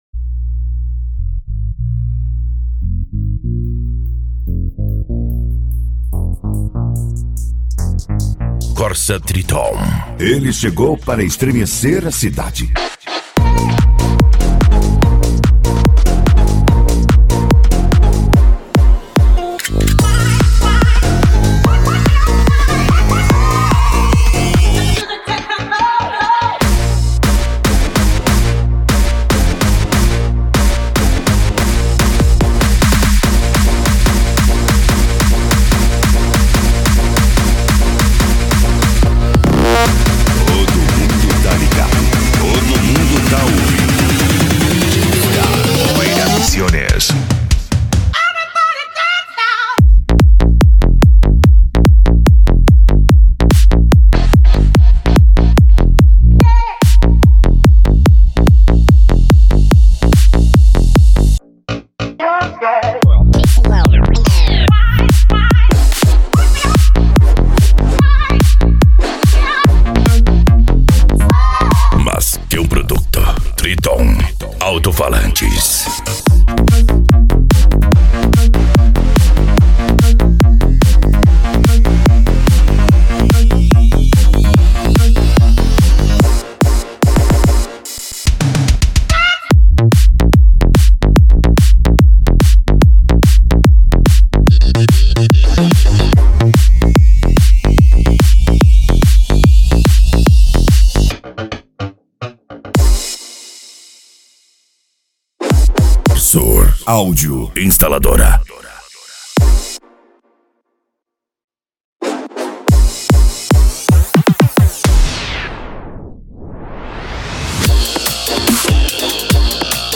Deep House
Electro House
Psy Trance
Remix